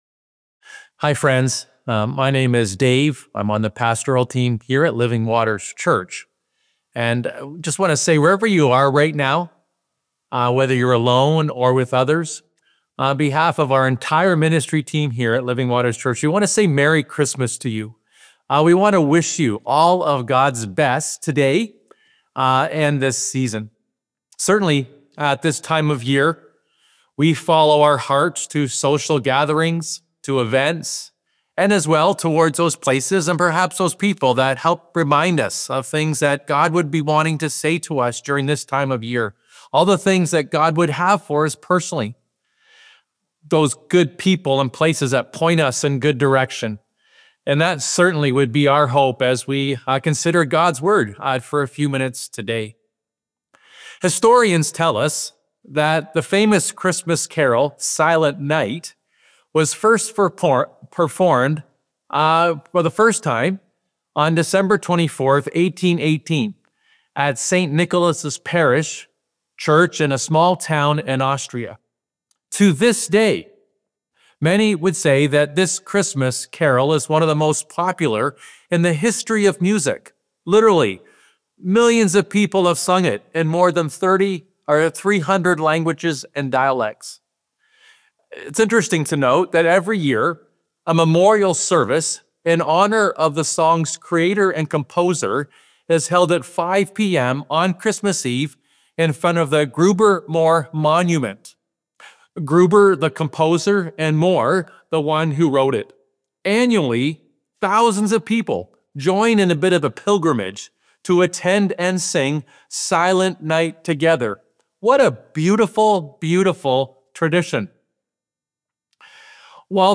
A Christmas Eve Homily